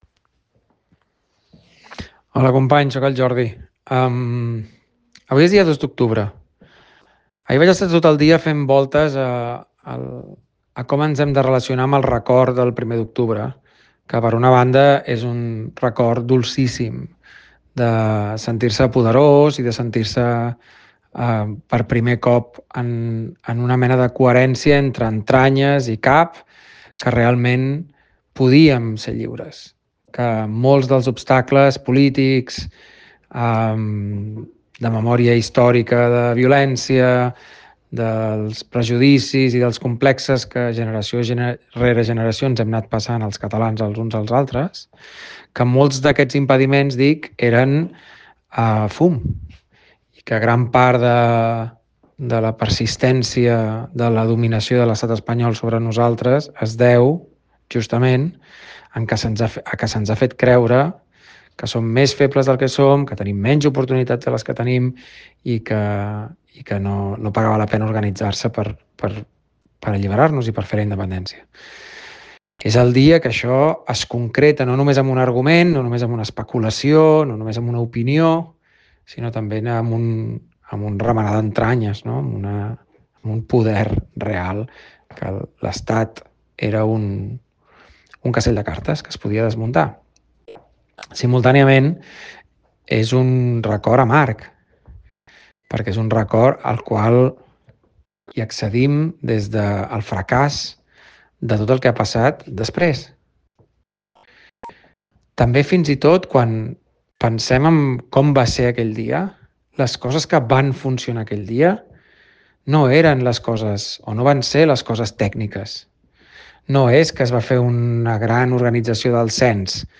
Aquesta és la transcripció d’un missatge en àudio adreçat a la gent d’Alhora l’endemà del primer d’octubre, en què reflexiono sobre el significat agredolç d’aquella jornada: un dia que va demostrar la possibilitat real de la independència però que va quedar sense continuïtat.
Missatge-de-Jordi-Graupera-sobre-l1-doctubre.mp3